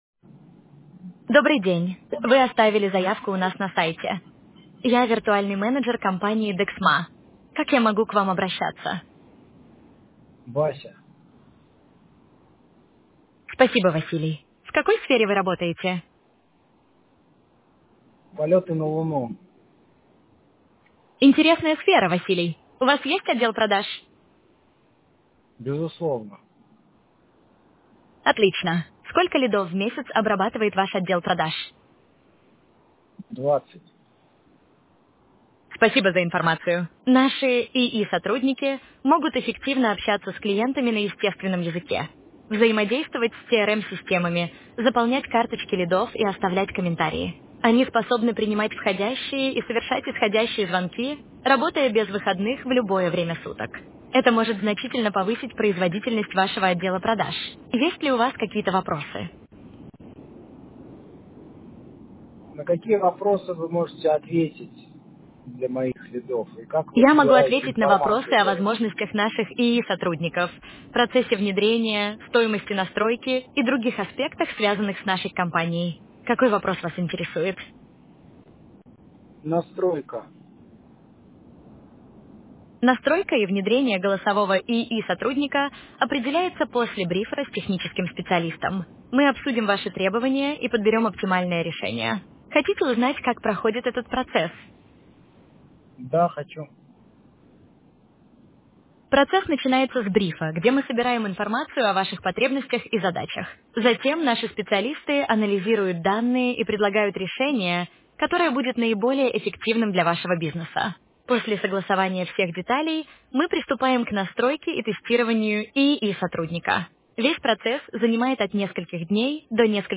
Используем самые современные языковые модели и технологии благодаря которым агенты общаются как "живые люди"
Консультация AI-Ассистента для B2B
• Обладают приятным «живым» голосом